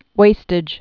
(wāstĭj)